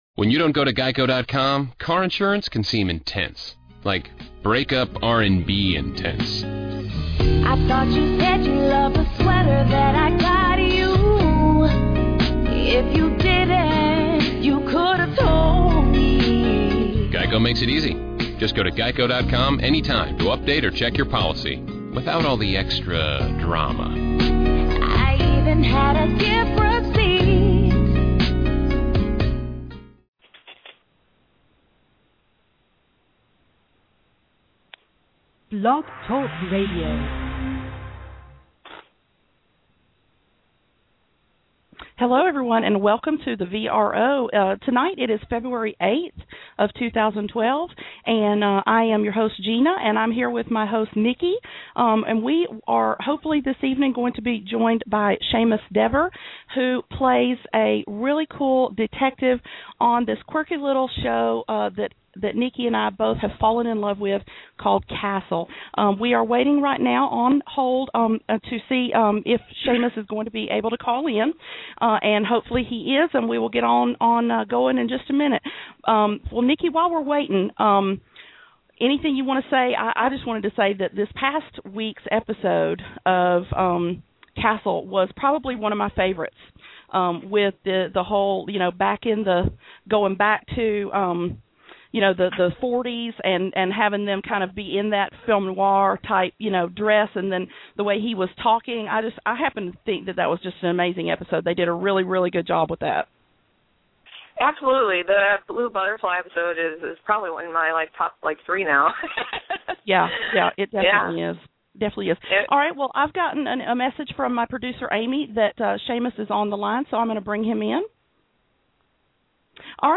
Seamus Dever 2012 interview